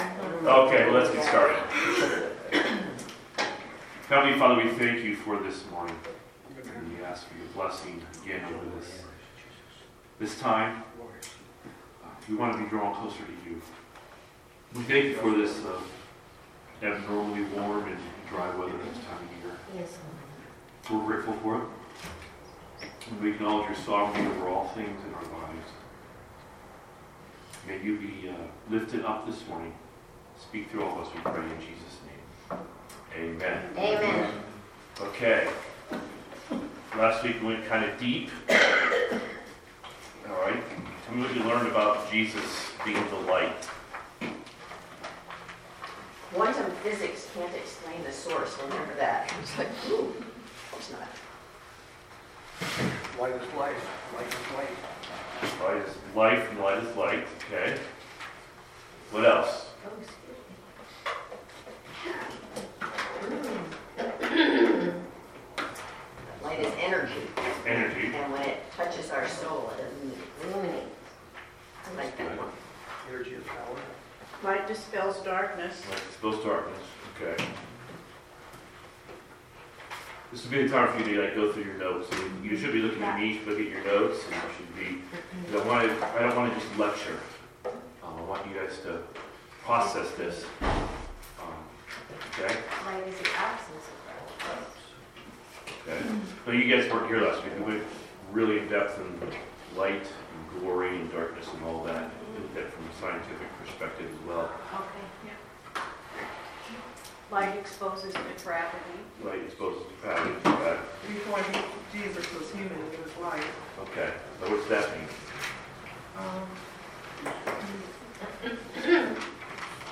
Sunday School from Bible Chapel of Auburn, WA